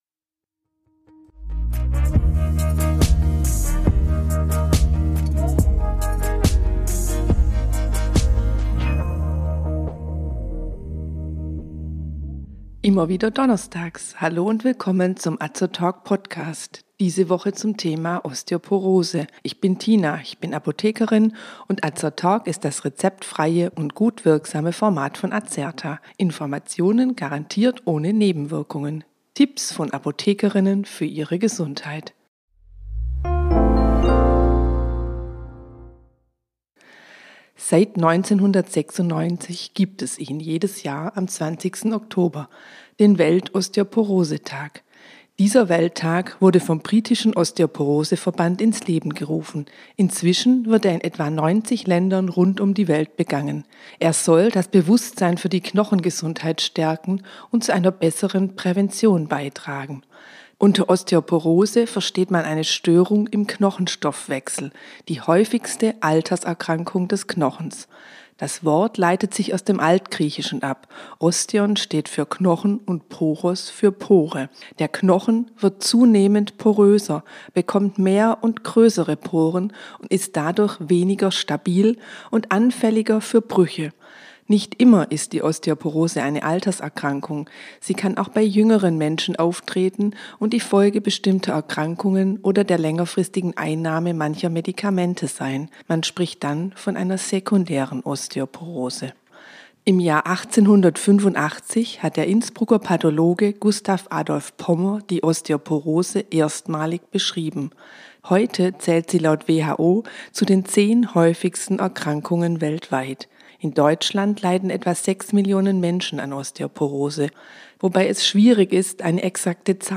Azertalk: Von Apothekerinnen für Ihre Gesundheit.